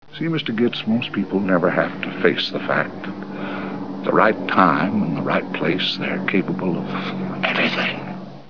Sound Bites: